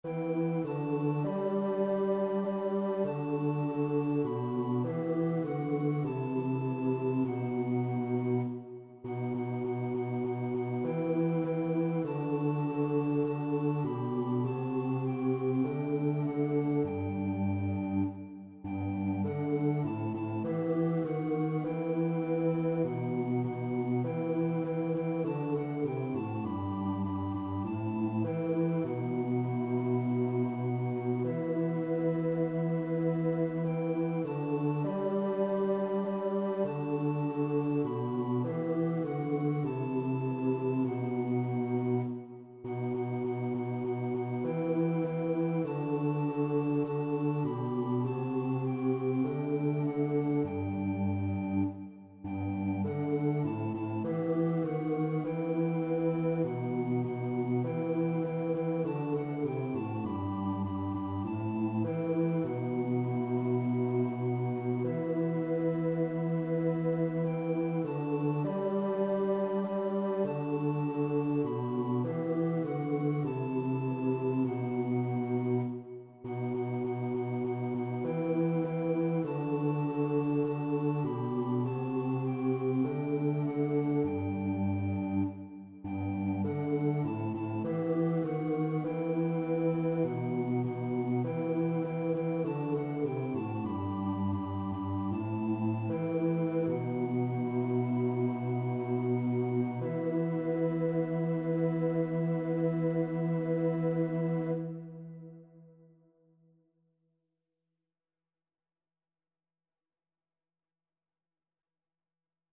4ª Voz